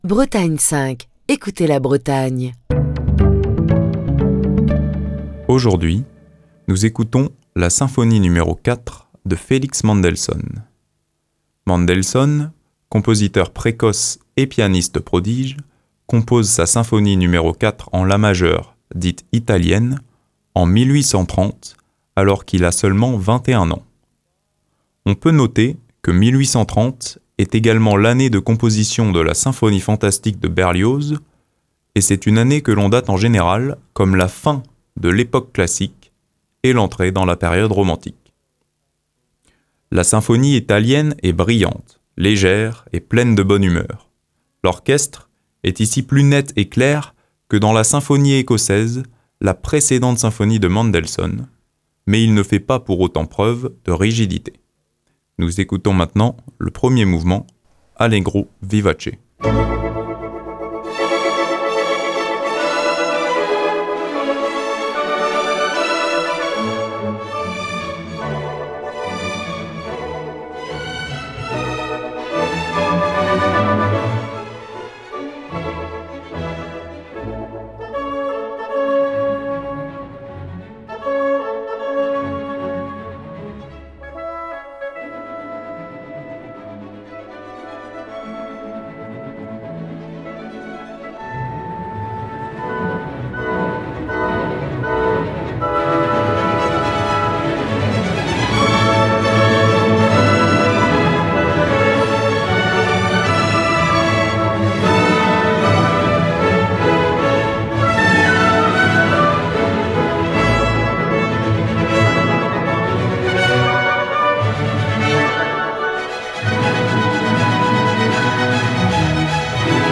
une version enregistrée en 1984